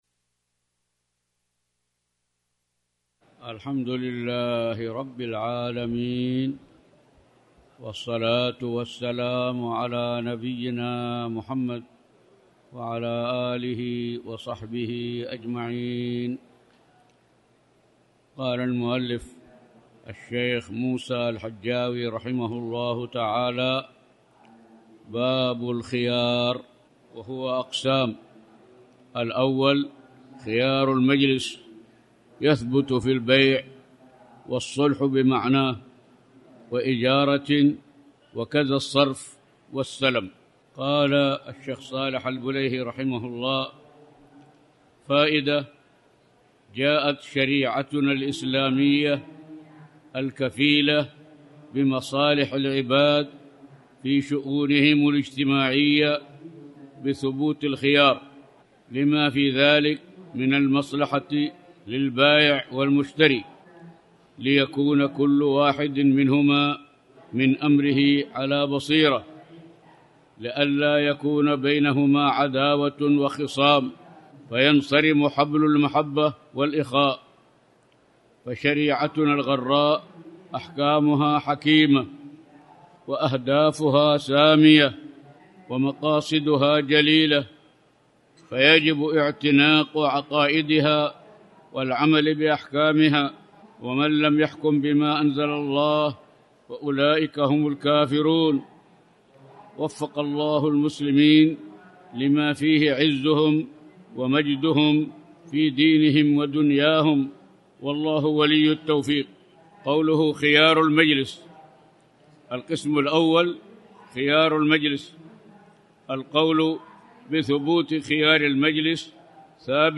تاريخ النشر ٤ صفر ١٤٣٩ هـ المكان: المسجد الحرام الشيخ